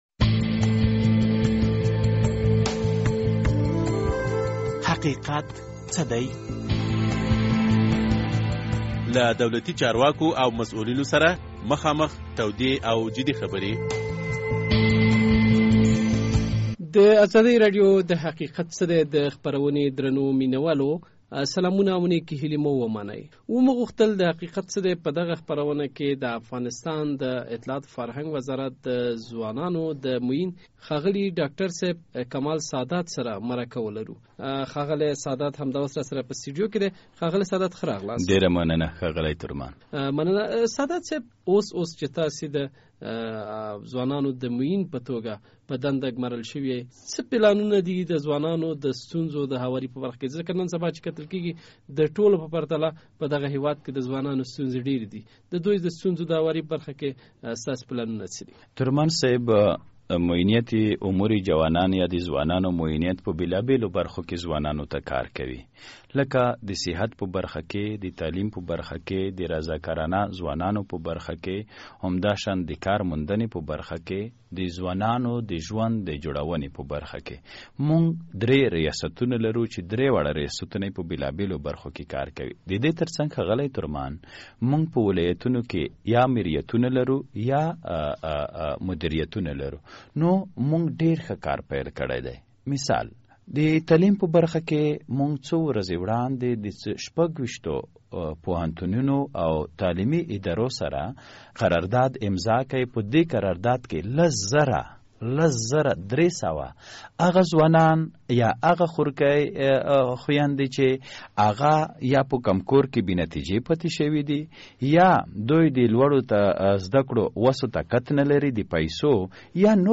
د حقیقت څه دی په دې خپرونه کې مو د اطلاعاتو او فرهنګ وزارت د ځوانانوله معین ډاکټر کمال سادات سره مرکه کړې او د ځوانانو موجوده ستونزې مو ورسره څېړلي دي.